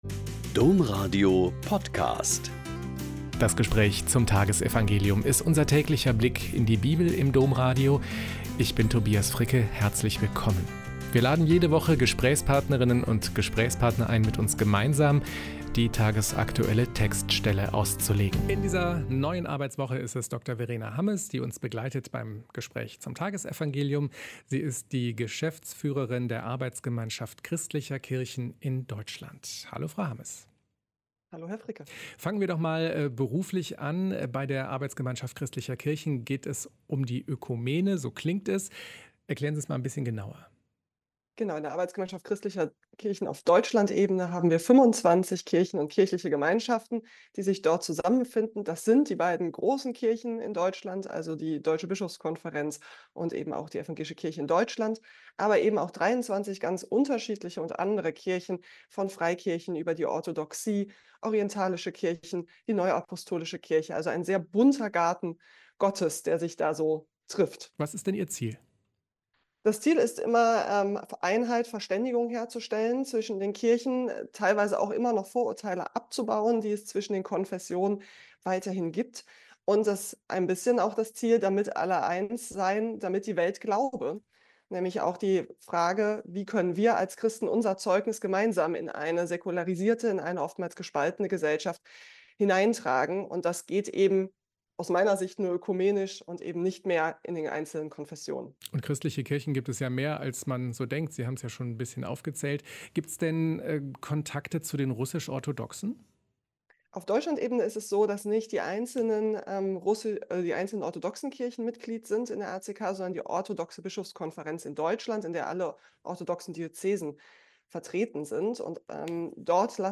Mt 7,1-5 - Gespräch